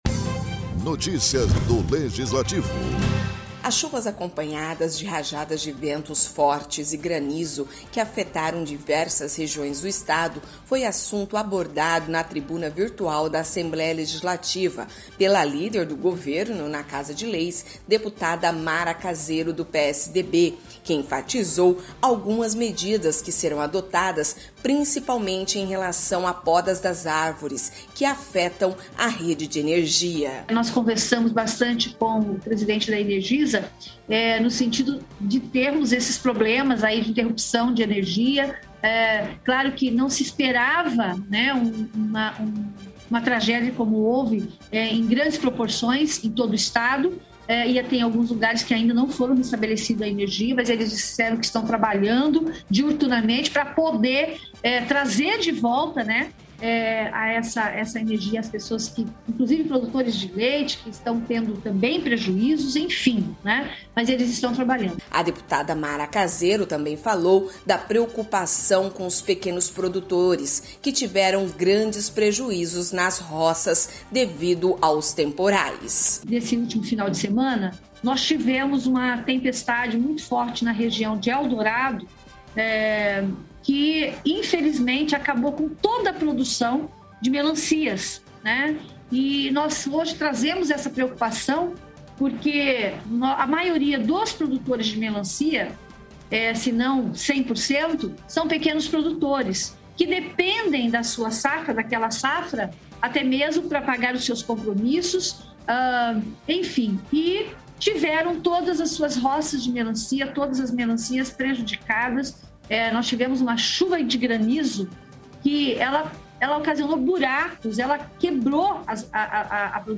As chuvas acompanhadas de rajadas de ventos fortes e granizo que afetaram diversas regiões do Estado foi assunto abordado na tribuna virtual da Assembleia Legislativa, pela líder do Governo na Casa de Leis, deputada Mara Caseiro do (PSDB), que enfatizou algumas medidas que serão adotadas principalmente em relação a podas das árvores que afetam a rede energia.